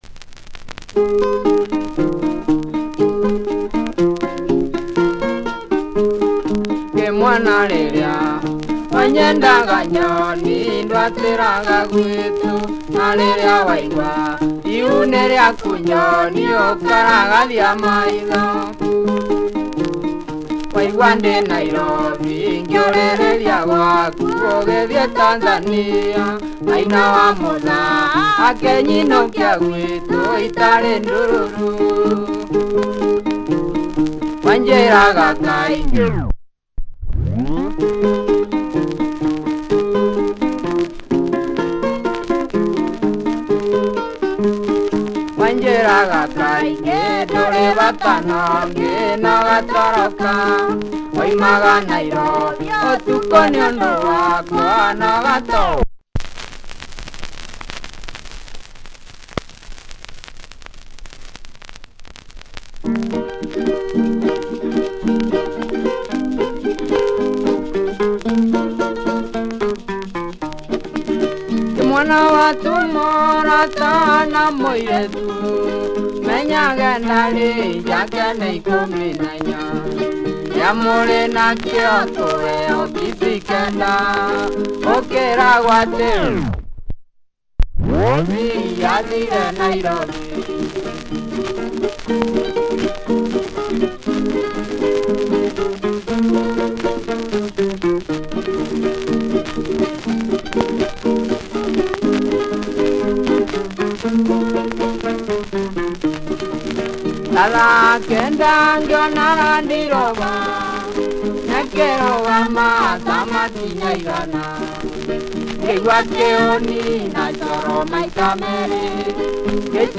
Traditional Kikuyu sounds